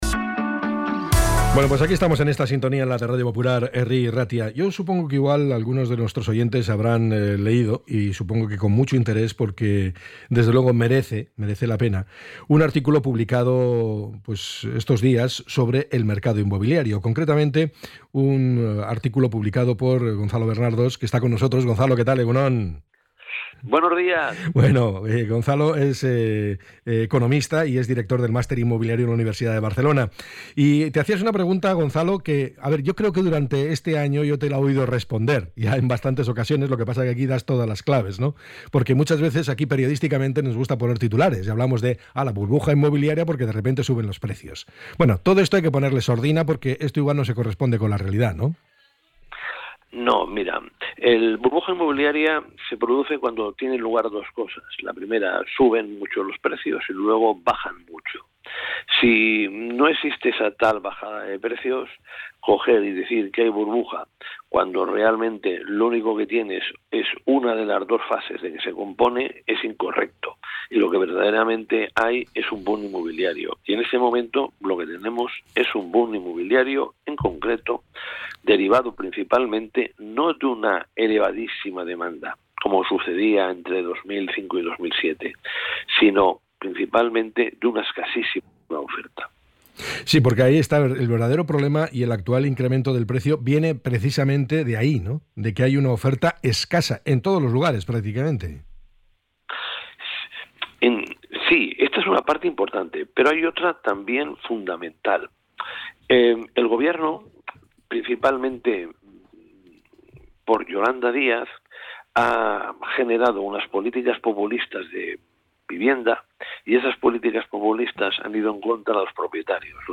ENTREV.-GONZALO-BERNARDOS.mp3